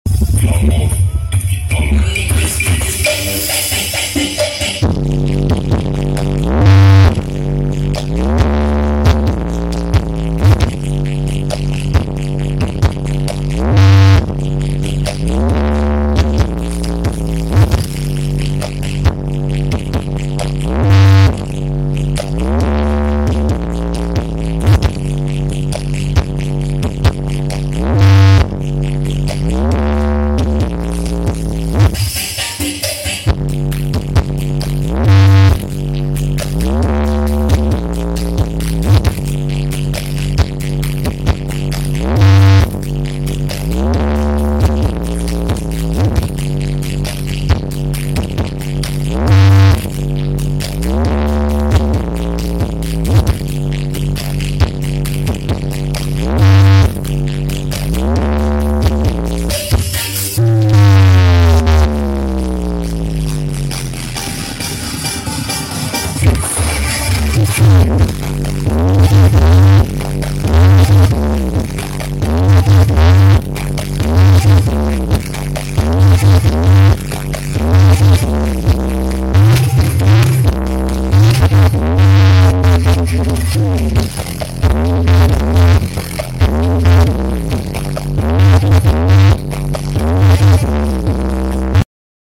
upgrade sound sound effects free download